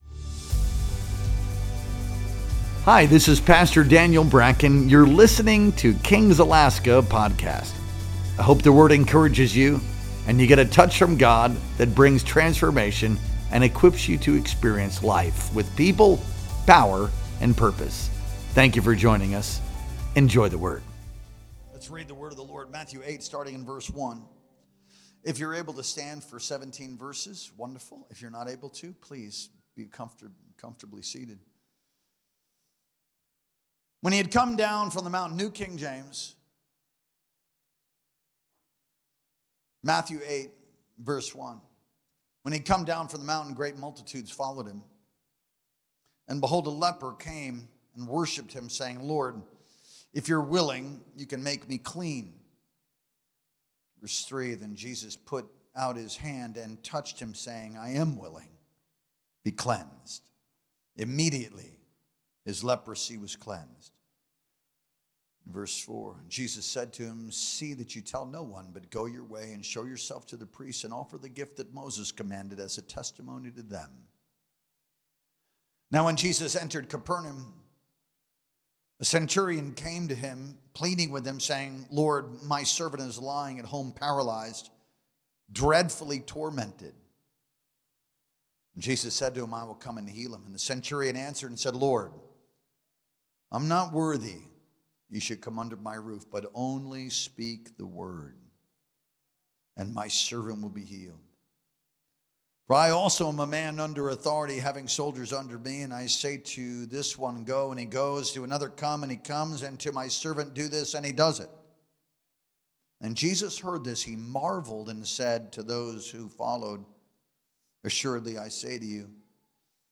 Our Sunday Night Worship Experience streamed live on March 23rd, 2025.